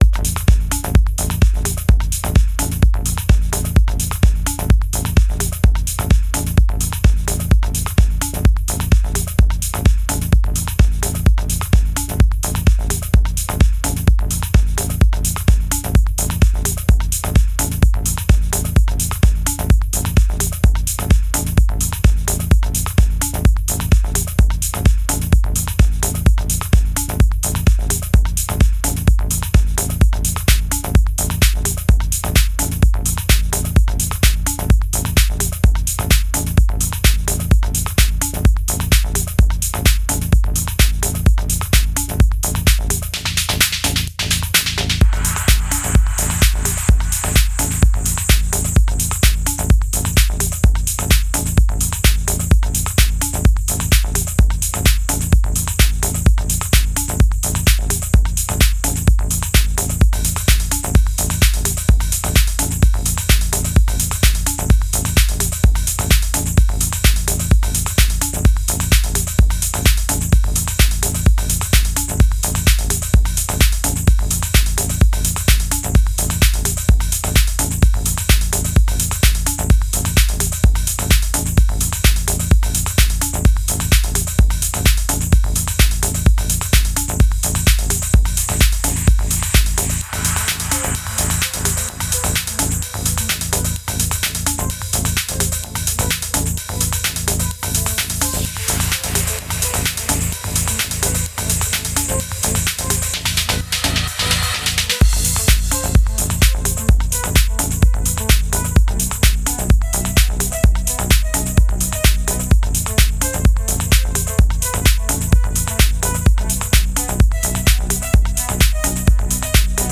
Жанр:Techno